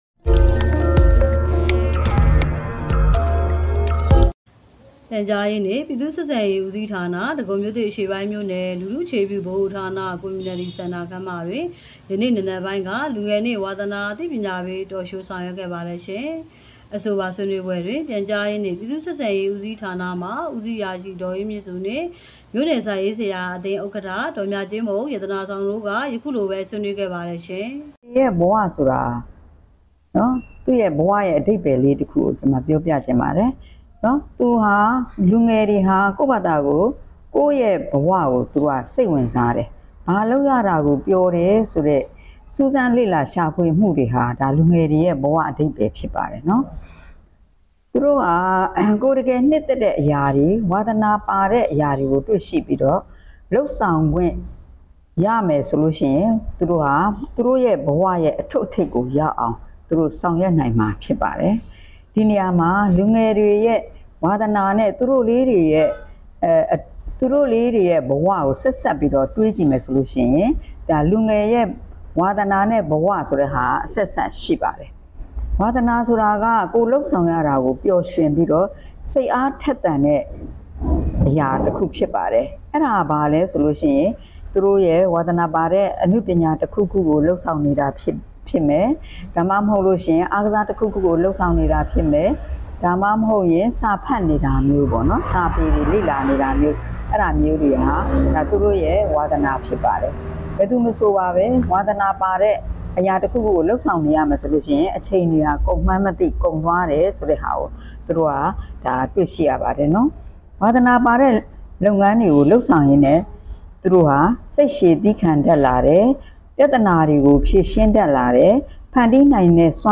ဒဂုံမြို့သစ်(အရှေ့ပိုင်း)မြို့နယ်တွင် လူငယ်နှင့် ဝါသနာအသိပညာပေး Talk ...